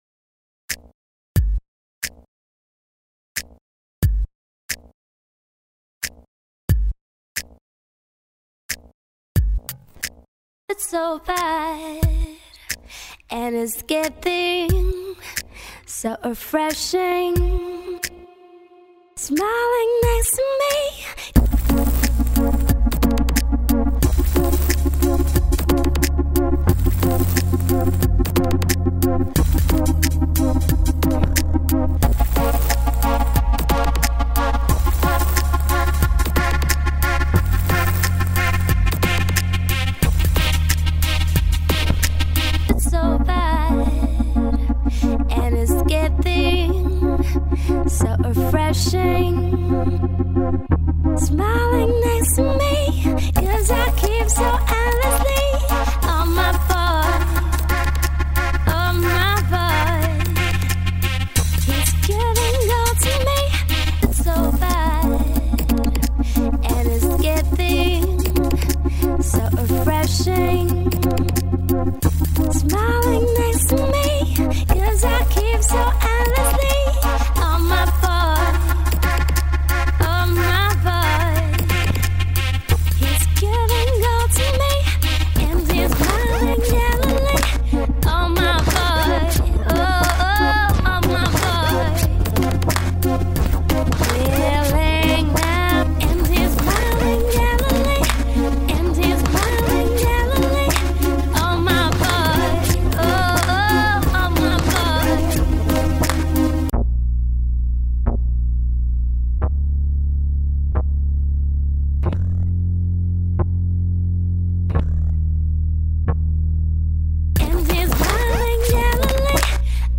Hier habe ich einige Loops aus dem Construction-Kit